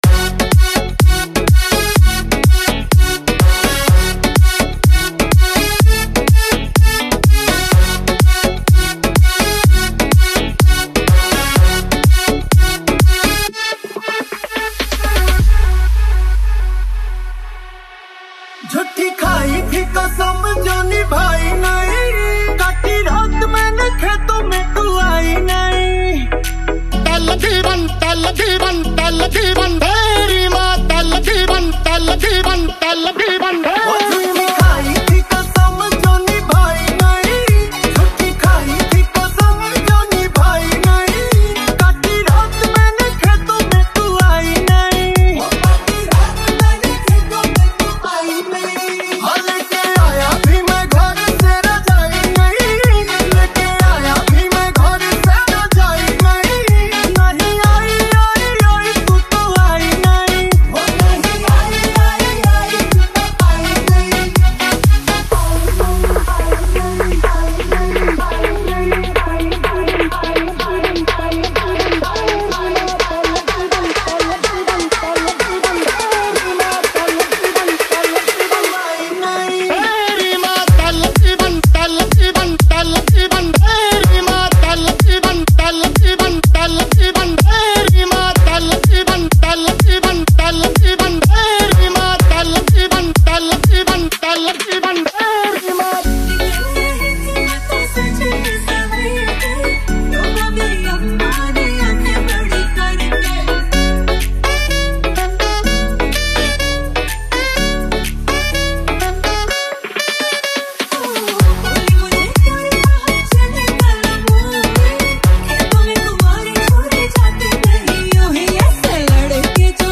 • Live Electric & Acoustic Guitars